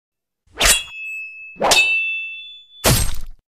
เสียงเอฟเฟค เสียงฟันดาบ
สร้างโดย: เสียงการต่อสู้ด้วยดาบ
หมวดหมู่: เสียงต่อสู้อาวุธ
คำอธิบาย: ด้านบนคือเสียงเอฟเฟค เสียงฟันดาบ เสียงการต่อสู้ด้วยอาวุธโลหะที่คมความ... การสวดดาบอย่างรวดเร็วและเด็ดขาดทุกครั้งที่มันปรากฏออกมาแสดงถึงความคมชัดของดาบ คุณสามารถใช้เอฟเฟกต์เสียงการต่อสู้นี้สำหรับฉากในภาพยนตร์ประวัติศาสตร์โบราณที่มีการต่อสู้...